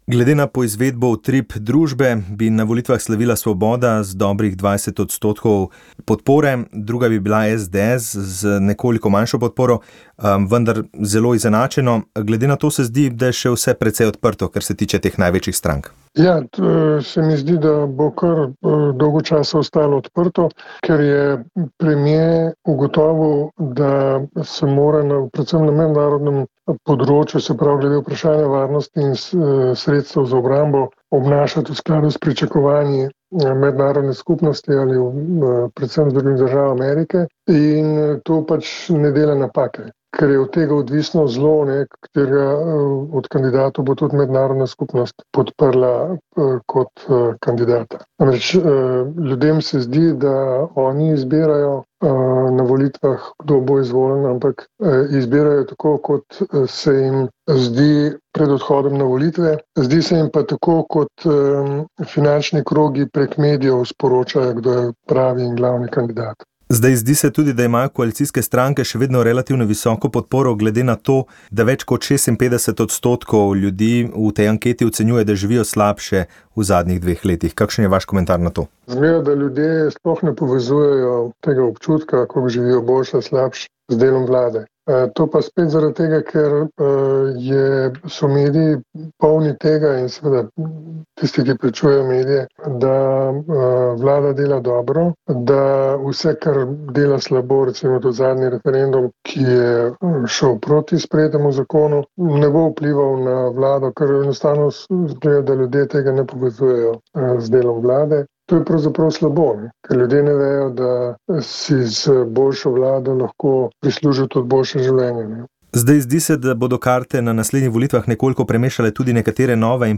Informativni prispevki